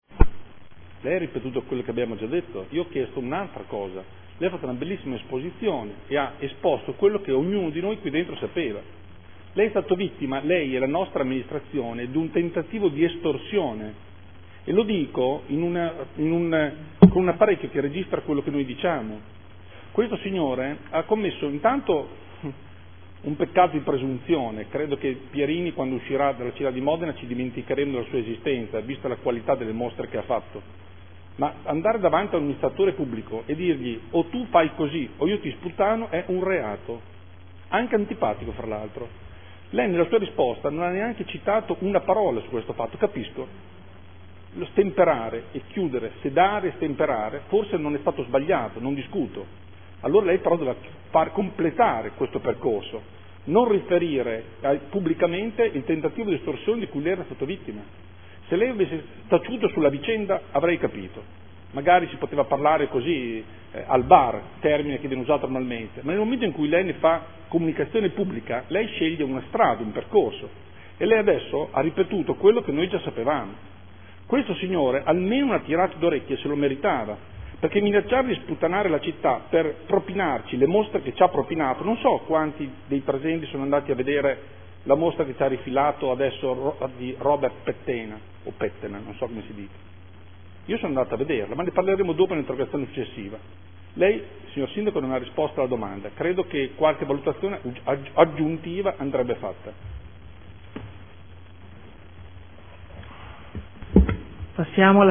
Andrea Galli — Sito Audio Consiglio Comunale
Seduta del 09/03/2015 Replica a risposta del Sindaco.